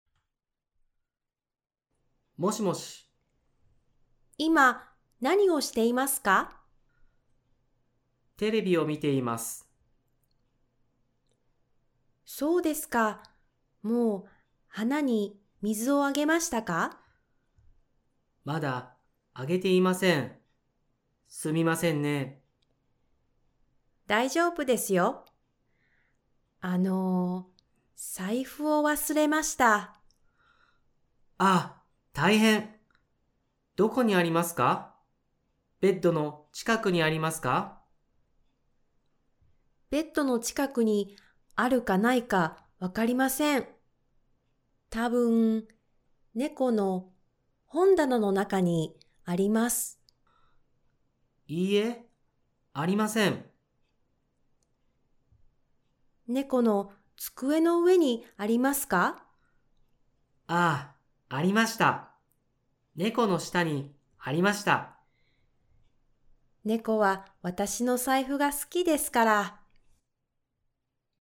GL6-Conversation.mp3